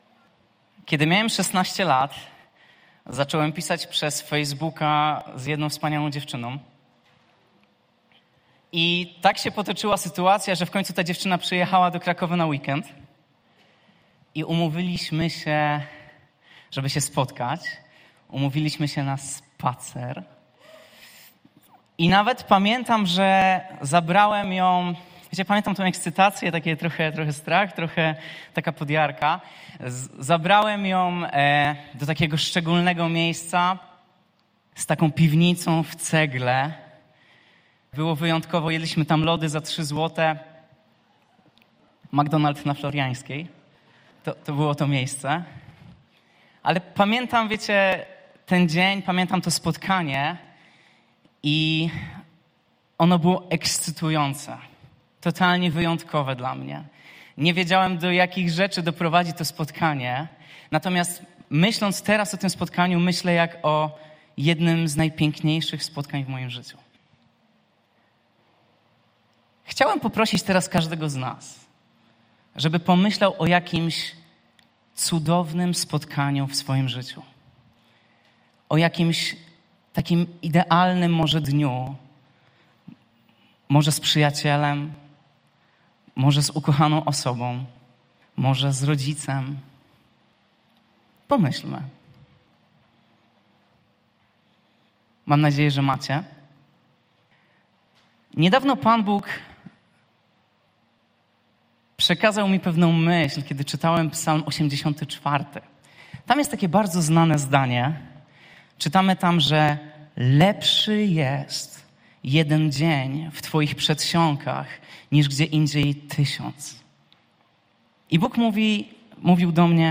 Pytania do refleksji po kazaniu w dniu 21.04.2024